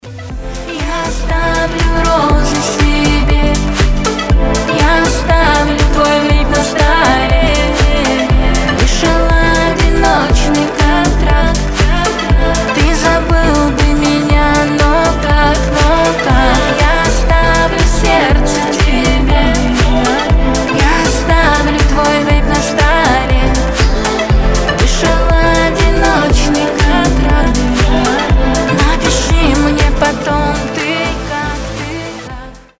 • Качество: 320, Stereo
мелодичные
нежные